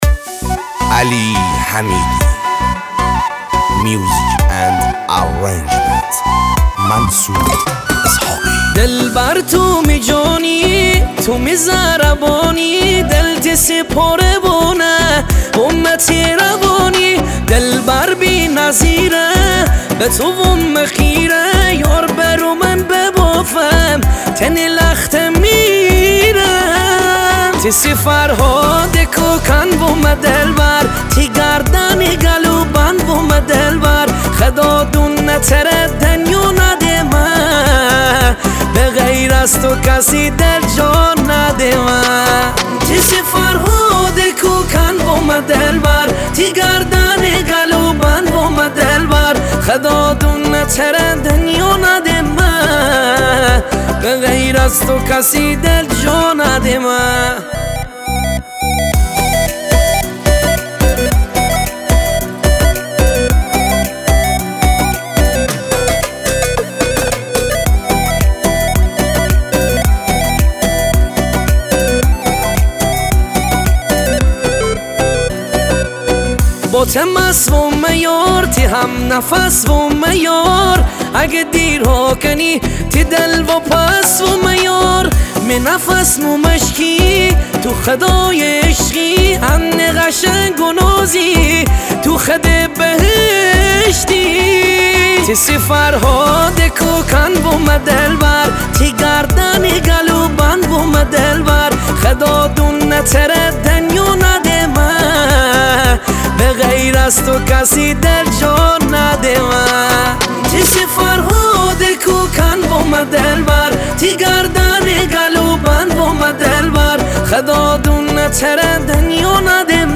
موزیک مازندرانی